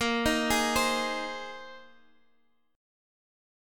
Listen to Bb7sus2sus4 strummed